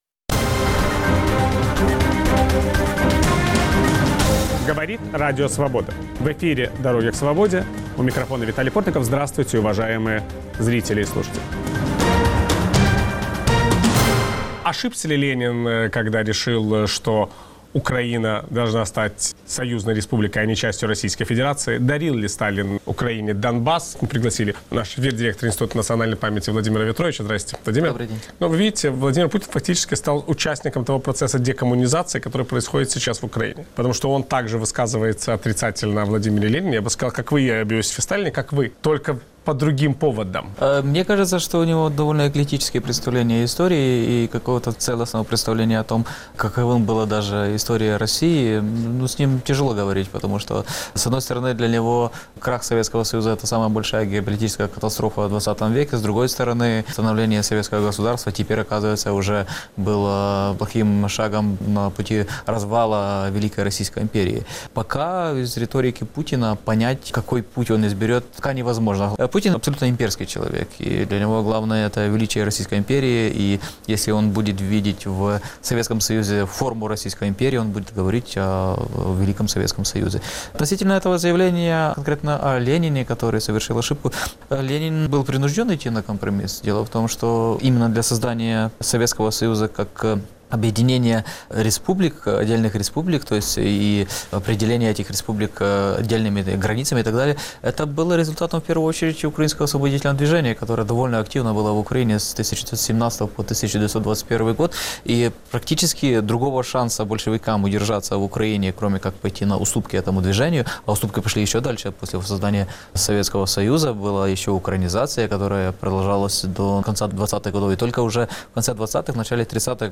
Как проходит украинская декоммунизация? Собеседник Виталия Портникова - директор украинского Института национальной памяти Владимир Вятрович.